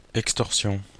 Ääntäminen
Ääntäminen Tuntematon aksentti: IPA: /ɛks.tɔʁ.sjɔ̃/ Haettu sana löytyi näillä lähdekielillä: ranska Käännös Substantiivit 1. extortion Suku: f .